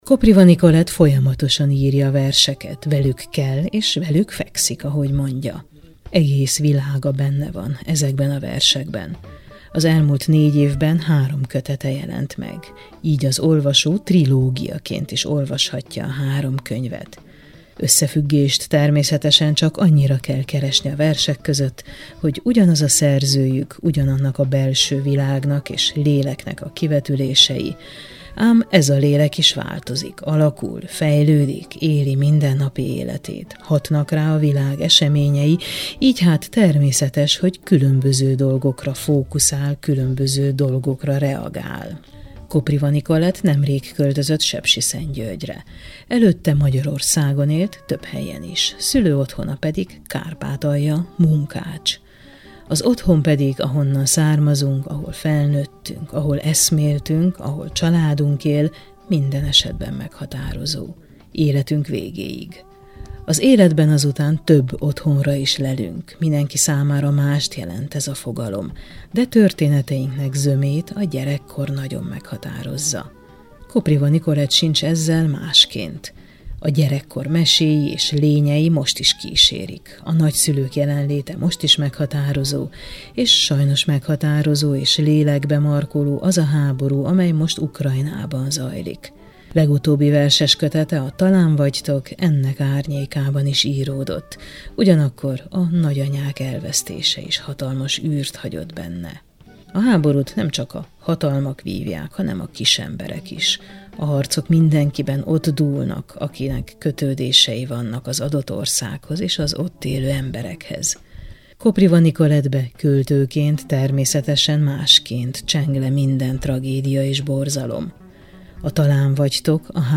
Az alábbiakban vele beszélgetünk, versekről, de életről, biztonságról és otthonról is.